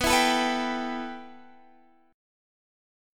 B7sus4#5 chord